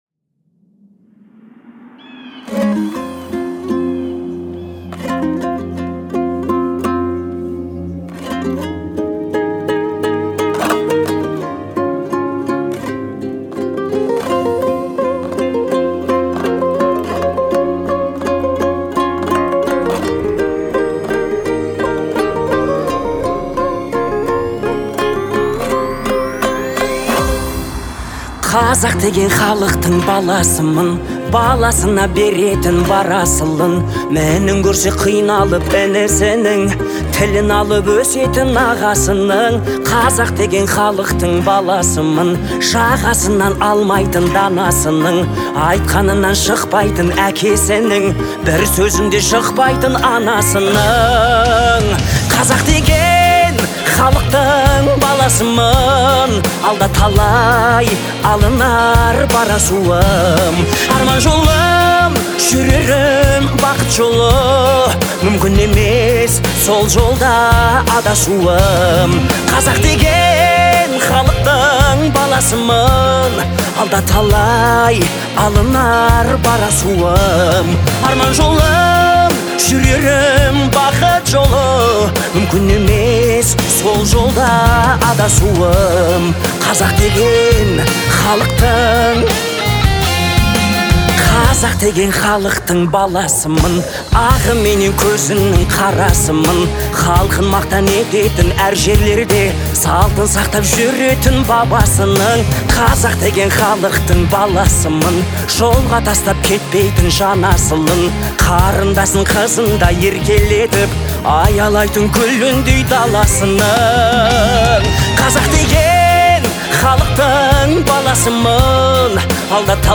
Звучание песни отличается мелодичностью и эмоциональностью